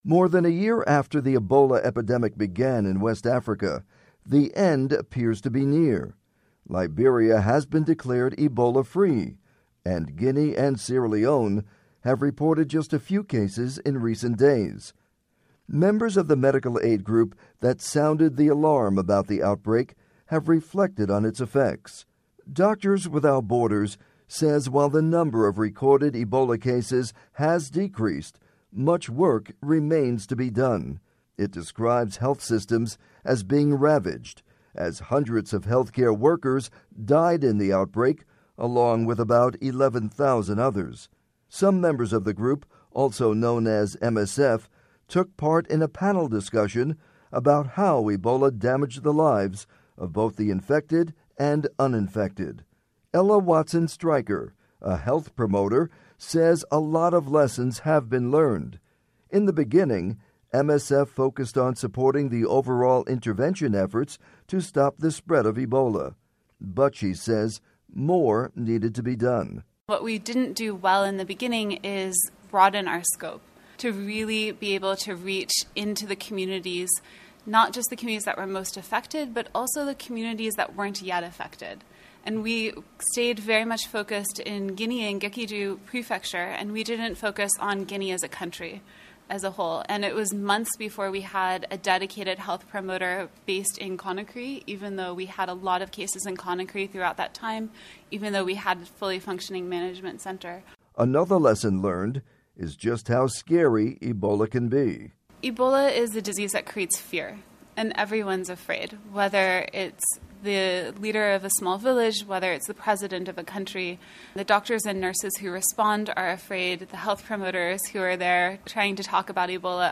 Some members of the group – also known as MSF – took part in a panel discussion about how Ebola damaged the lives of both the infected and uninfected.